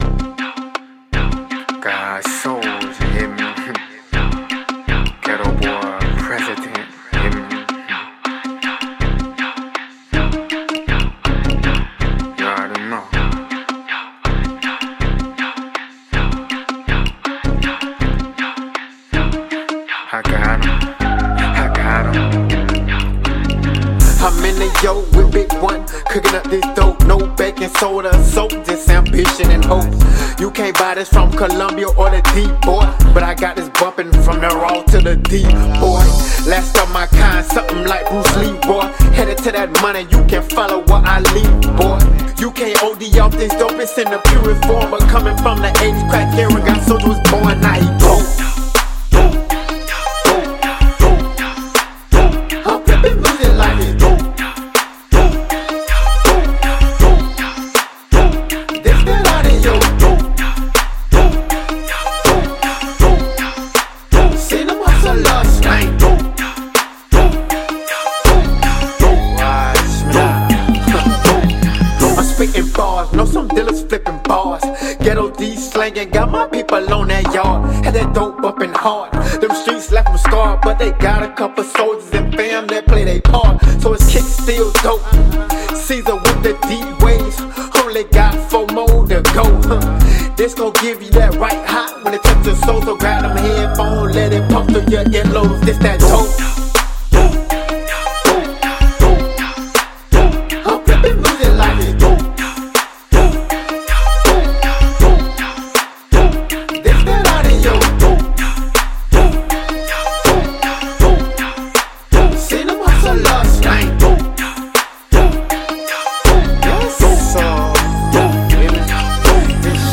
fla rap, fla hiphop, floridarap, floridahiphop